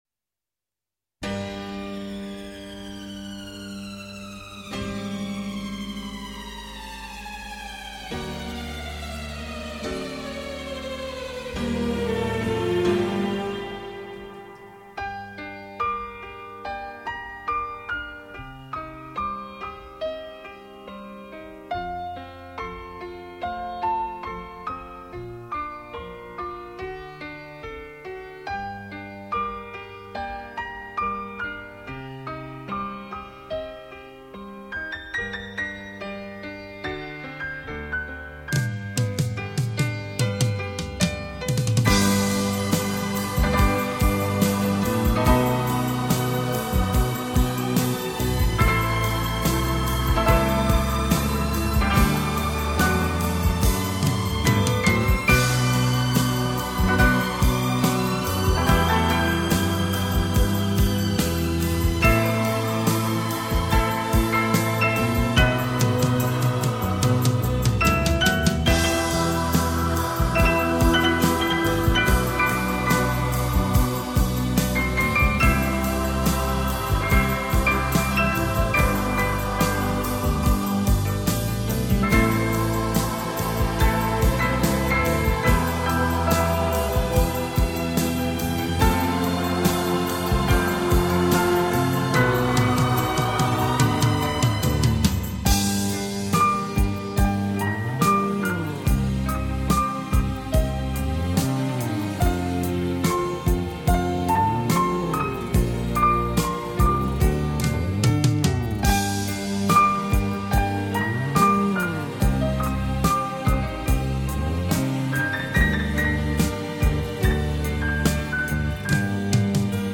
纯音乐
随着琴键地跳跃思绪也随之翻腾……